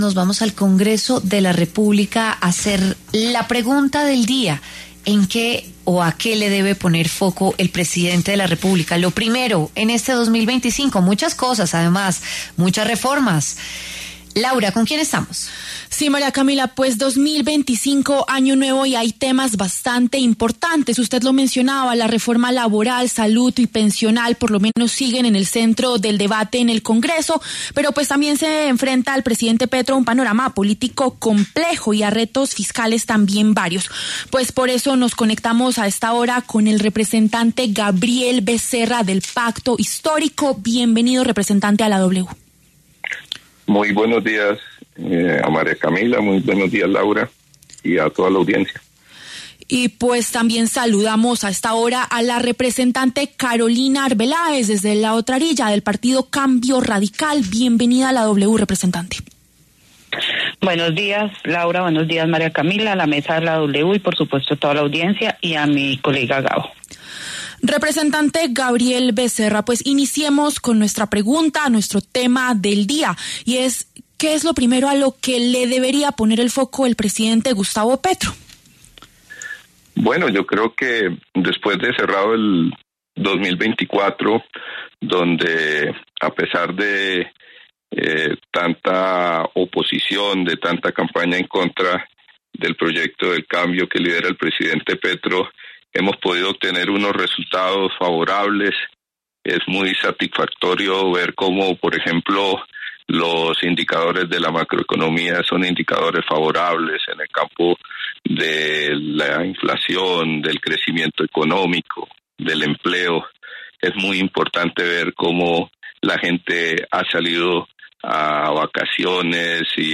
Gabriel Becerra, representante Pacto Histórico, y Carolina Arbeláez, representante Cambio Radical, debatieron sobre los temas más importantes que tendría que revisar el Gobierno Petro en este 2025.